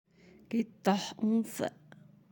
(qittah unsa)